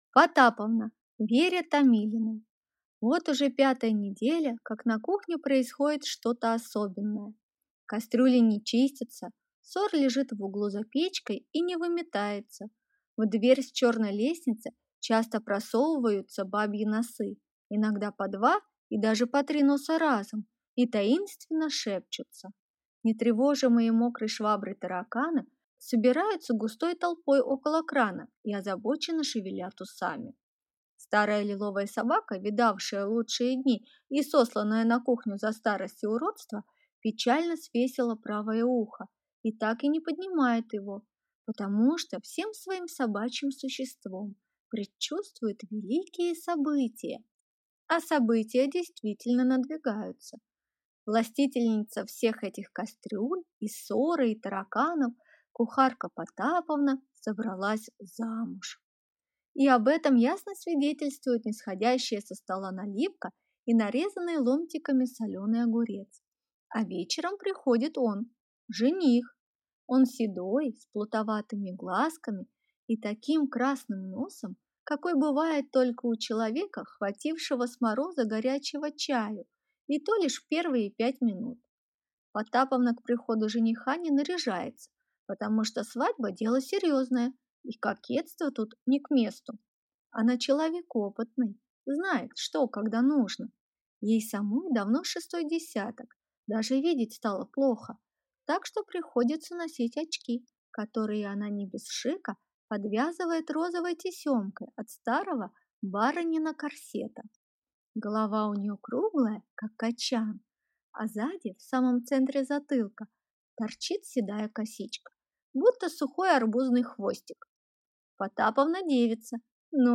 Aудиокнига Потаповна